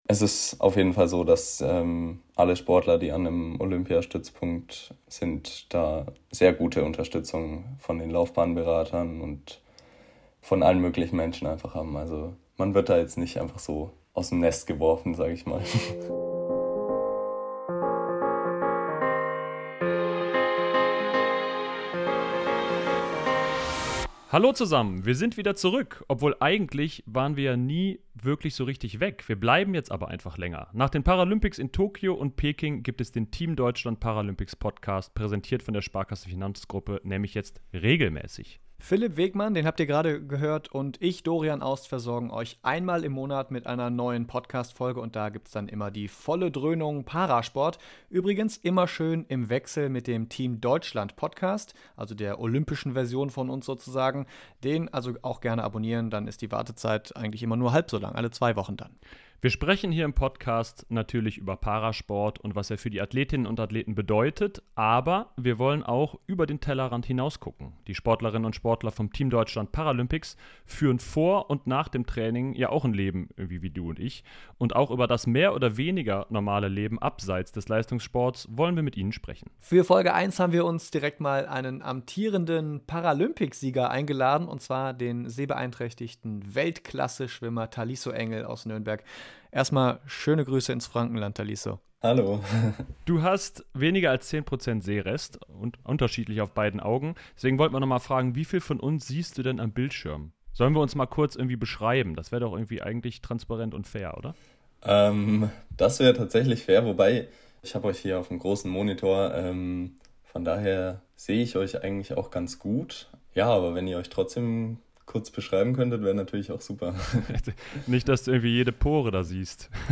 Beim Team Deutschland Paralympics Podcast sprechen wir mit dem sehbehinderten Nürnberger über seinen steilen Aufstieg, Eliteschulen im Sport und was sein verborgenes Talent ist.